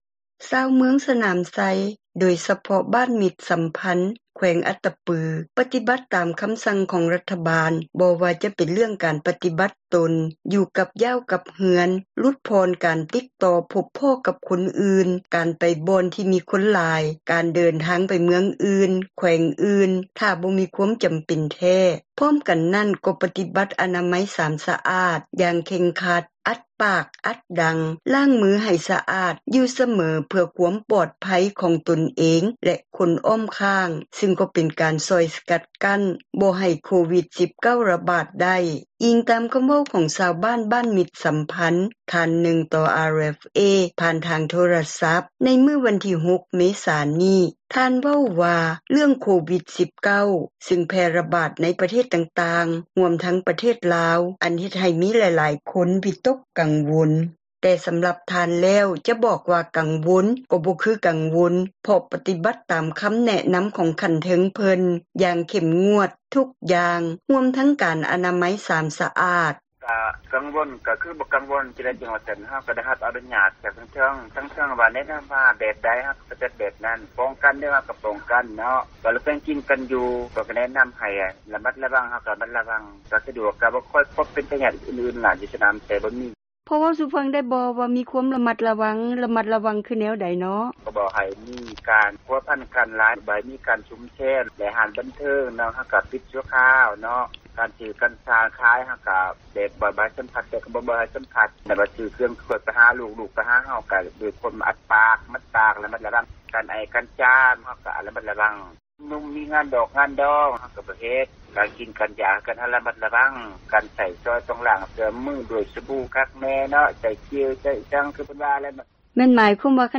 ພ້ອມກັນນັ້ນ ກໍປະຕິບັດອະນາມັຍ 3 ສະອາດ ຢ່າງເຄັ່ງຄັດ, ອັດປາກ ອັດດັງ ລ້າງມືໃຫ້ສະອາດຢູ່ສເມີ ເພື່ອ ຄວາມປອດພັຍ ຂອງຕົນເອງ ແລະຄົນອ້ອມຂ້າງ ຊຶ່ງກໍເປັນການຊ່ອຍສະກັດກັ້ນບໍ່ໃຫ້ ໂຄວິດ-19 ແຜ່ຣະບາດໄດ້, ອີງຕາມຄໍາເວົ້າຂອງ ຊາວບ້ານບ້ານມິດສັມພັນ ທ່ານນຶ່ງຕໍ່ RFA ຜ່ານທາງໂທຣະສັບ ໃນມື້ ວັນທີ 6 ເມສານີ້.